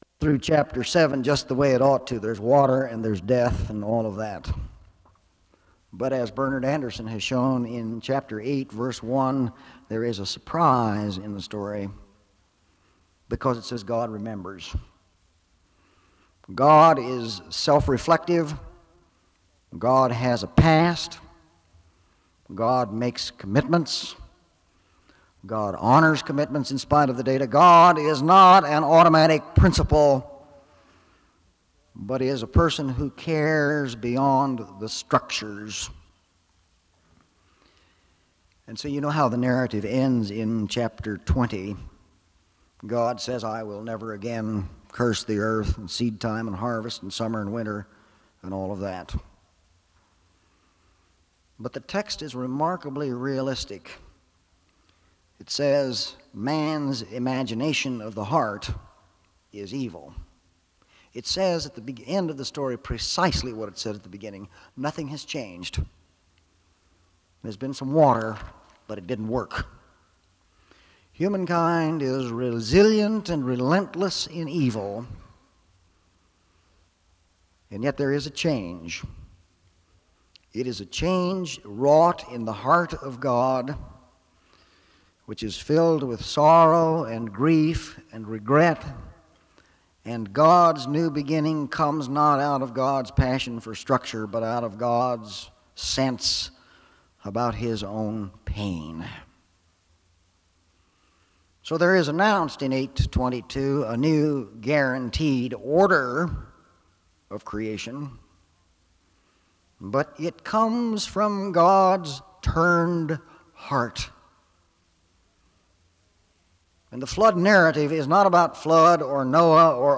File Set | SEBTS_Page_Lecture_Walter_Brueggemann_1981-10-07_B.wav | ID: c0238bc7-9099-4e50-a19d-917d50a79cd1 | Hyrax